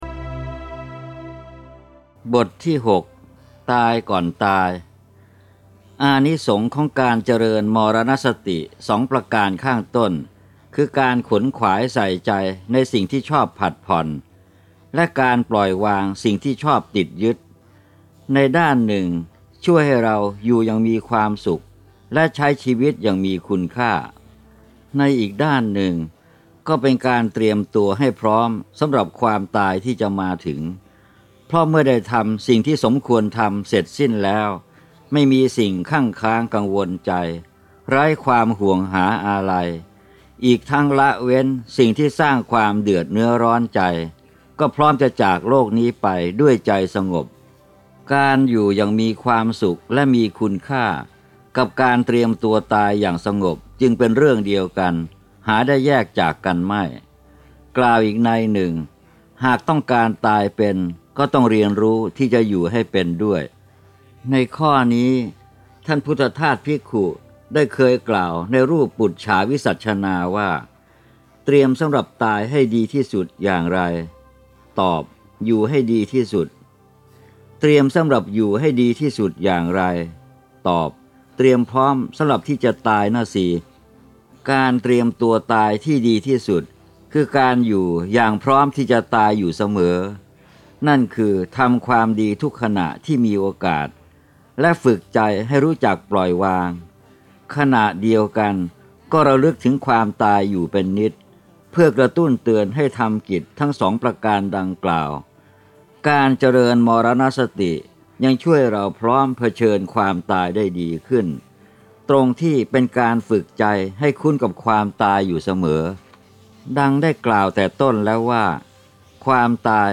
เสียงหนังสือ “ระลึกถึงความตายสบายนัก